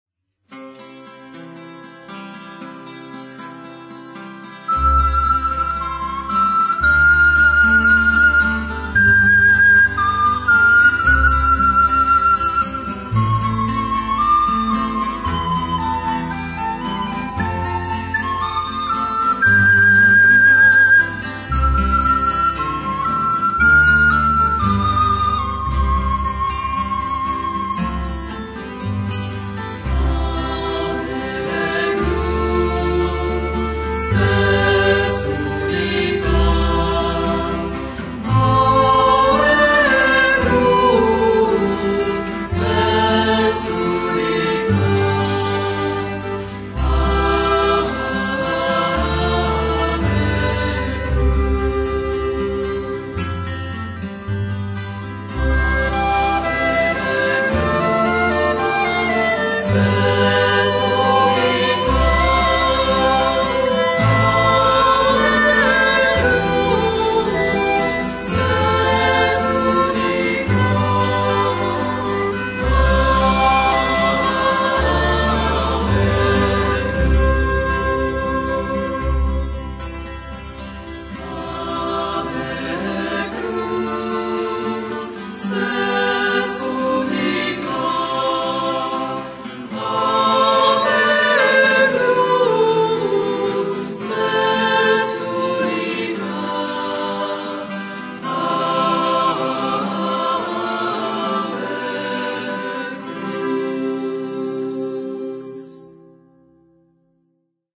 Vokálně instrumentální skupina, která hraje při mši svaté.
Nahráno 2000 v kostele sv. Jana Křtitele v Liberci-Rochlici.